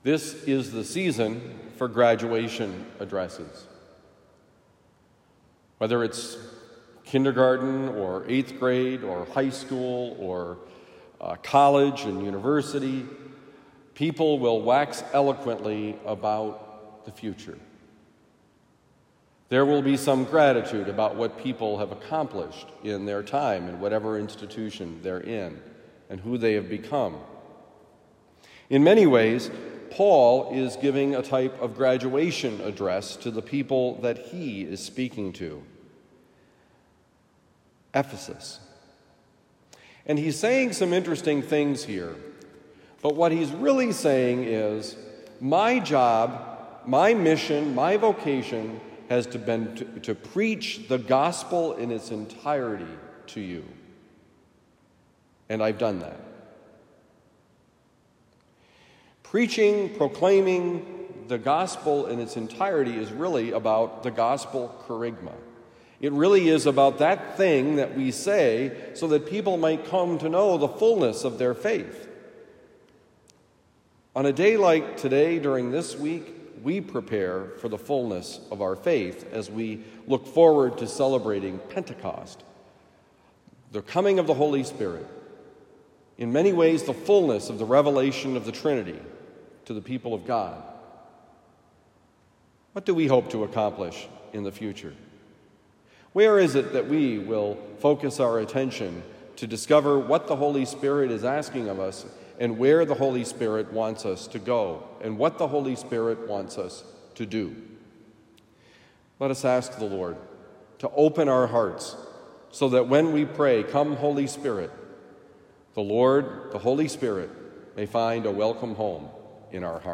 I did not shrink: Homily for Tuesday, May 23, 2023
Given at Christian Brothers College High School, Town and Country, Missouri.